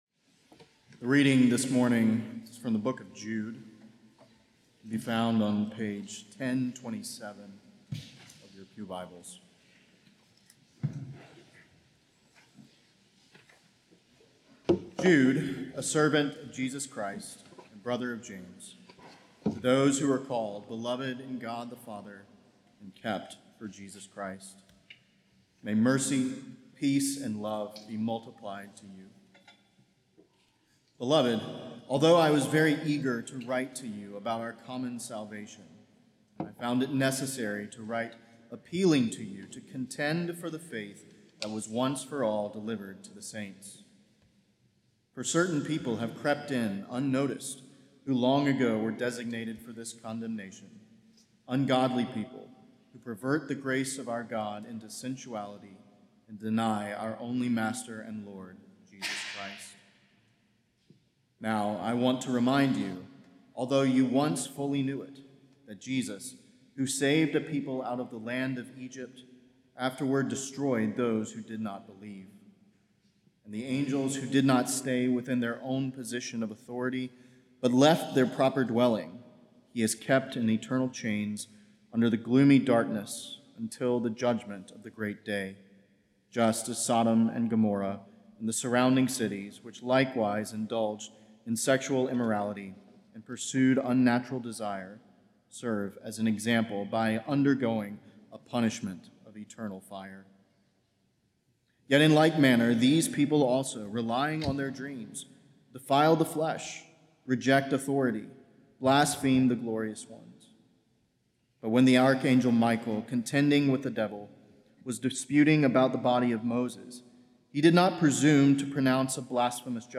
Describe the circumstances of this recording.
Beeson Divinity School Chapel Services